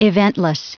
Prononciation du mot eventless en anglais (fichier audio)
Prononciation du mot : eventless